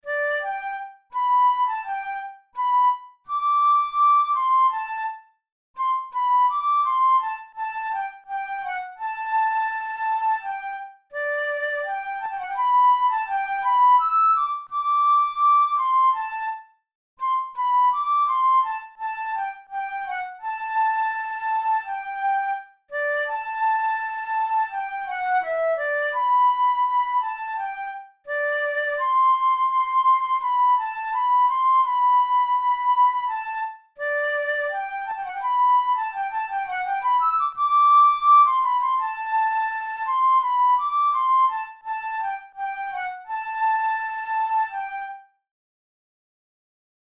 InstrumentationFlute solo
KeyG major
RangeD5–E6
Time signature4/4
Tempo84 BPM